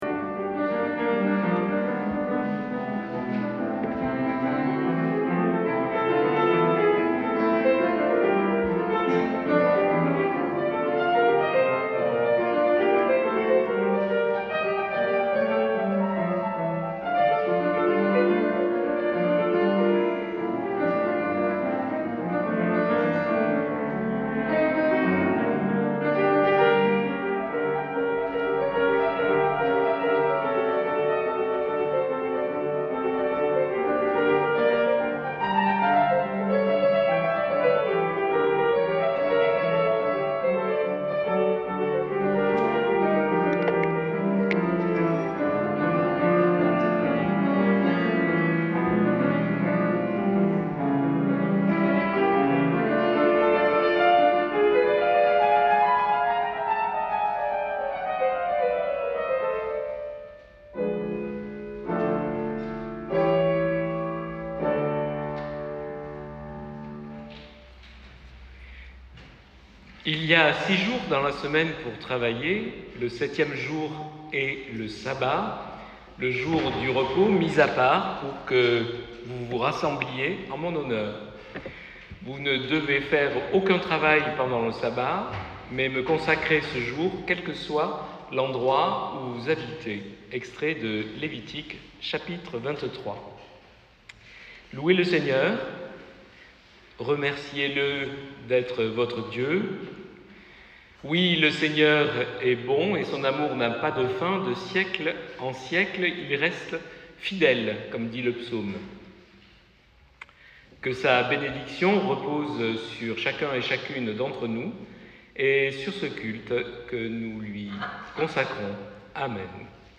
CULTE DU 23 JUIN
LA PRÉDICATION À PART